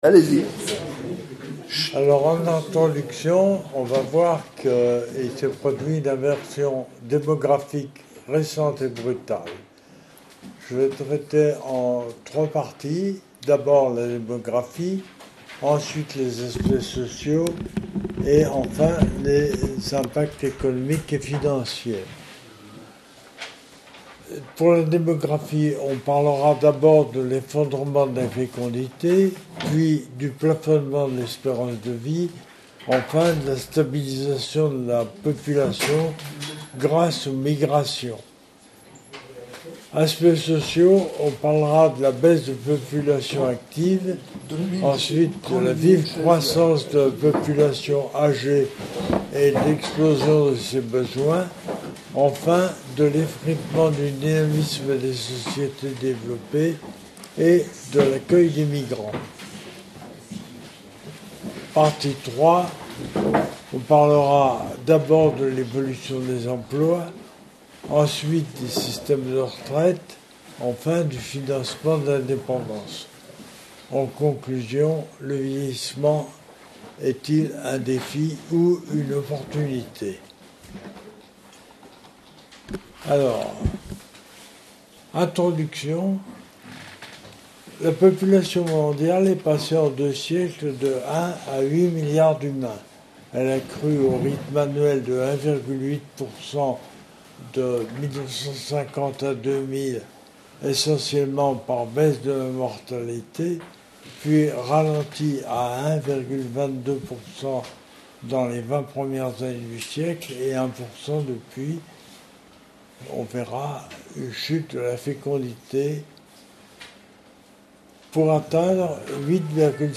Exposé à deux voix avec un de ses amis qui l'a assisté à lire son travail